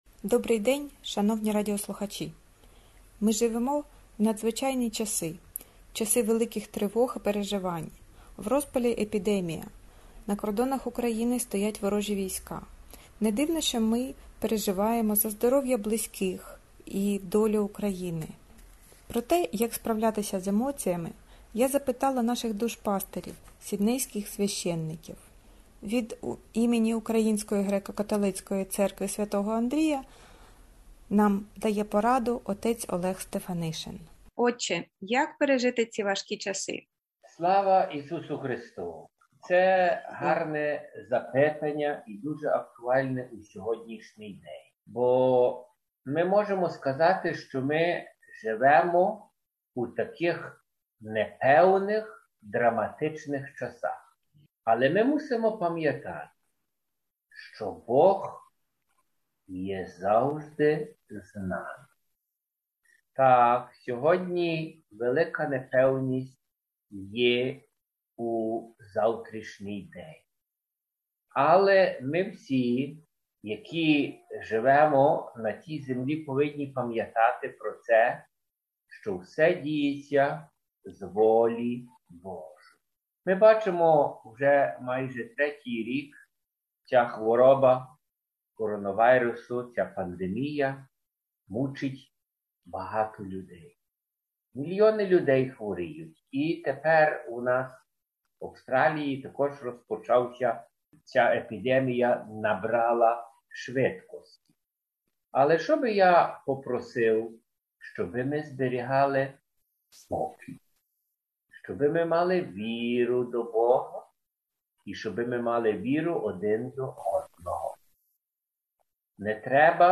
порадами діляться австралійські душпастирі різних українських конфесій